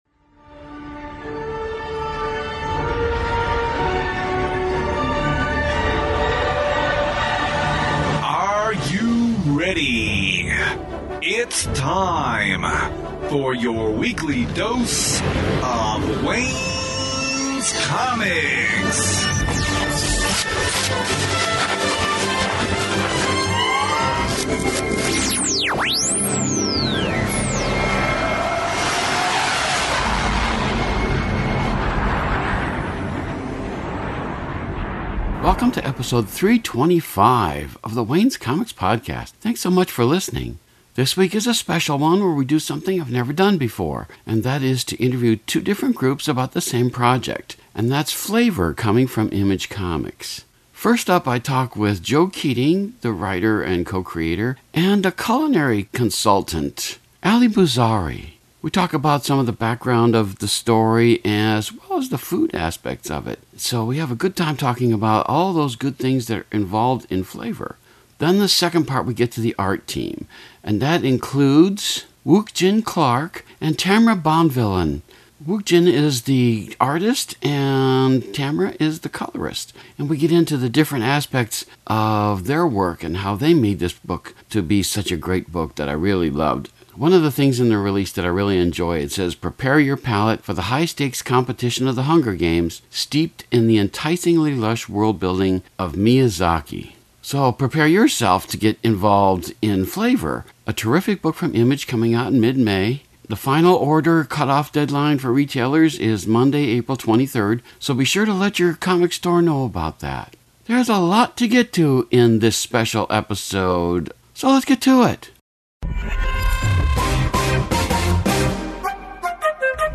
Landmark Episode 325 goes where this podcast has never gone before – Two interviews about the same great upcoming comic, Flavor from Image Comics!